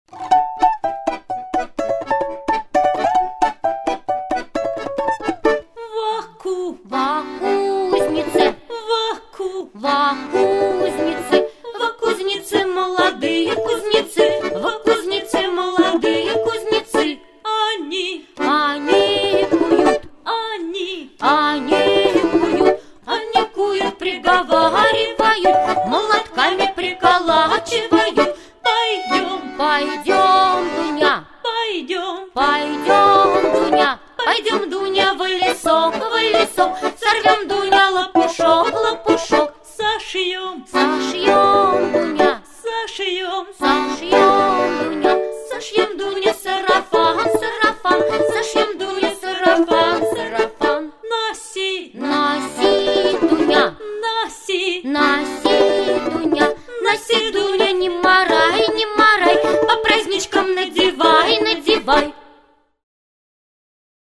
Народные мелодии хорошо подходят для активного слушания.
Слова и музыка народные.